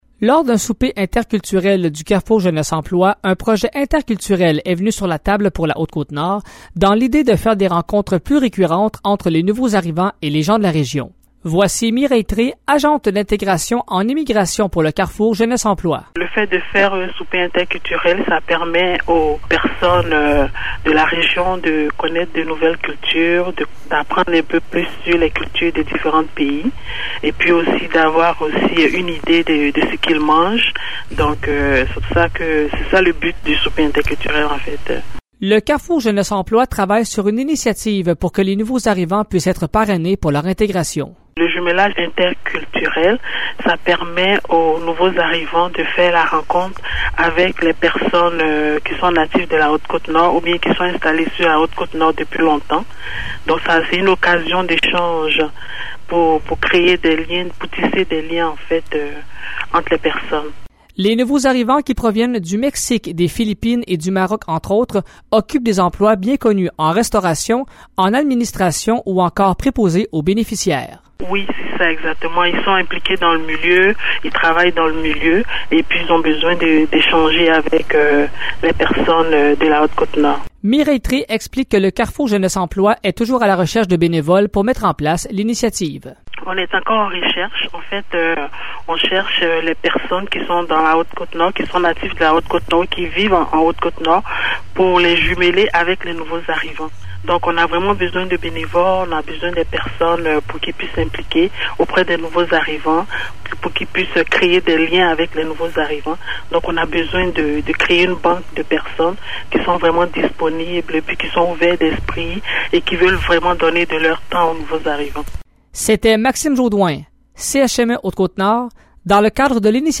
Voici le reportage